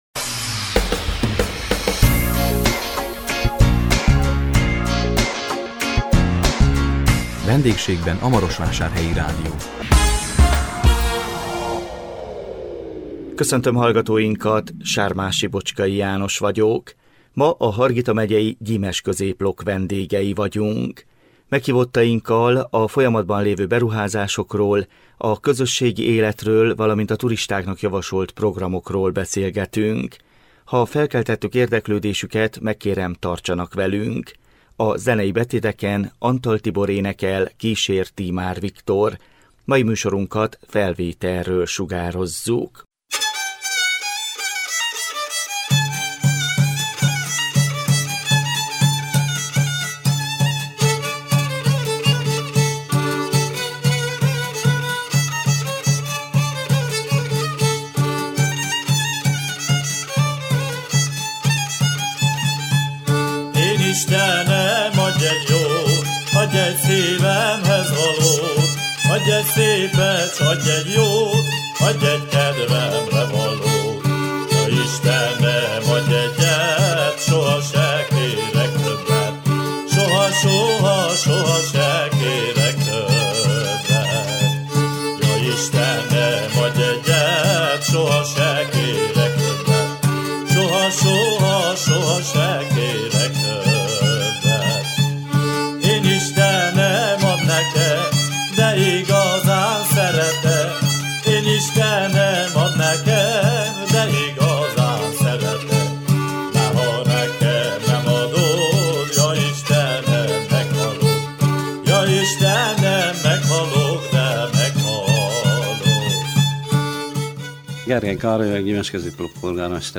A 2021 május 20-án jelentkező VENDÉGSÉGBEN A MAROSVÁSÁRHELYI RÁDIÓ című műsorunkban a Hargita megyei Gyimesközéplok vendégei voltunk. Meghívottainkkal a folyamatban lévő beruházásokról, a közösségi életről valamint a turistáknak javasolt programokról beszélgettünk.